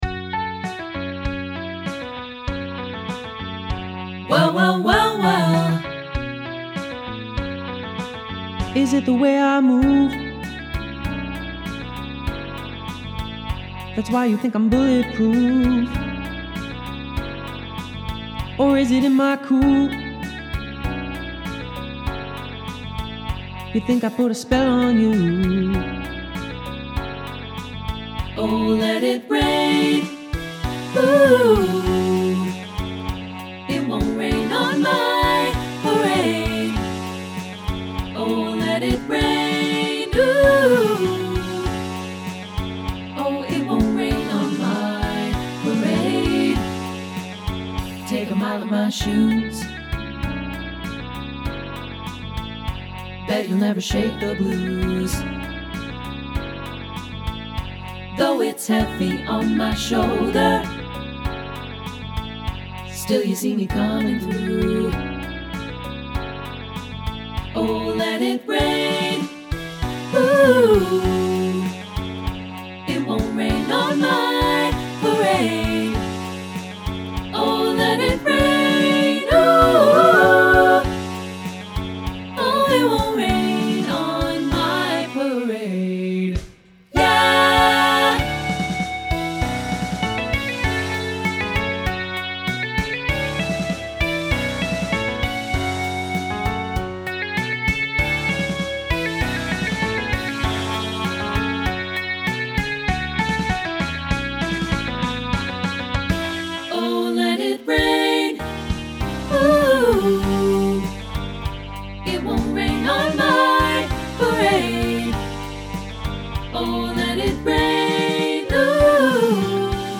contemporary choral SATB arrangement
Check out the studio demo (MIDI instruments + live voices):
Instrumentation: Piano, Guitar, Bass, Drumset
SATB Pop Choral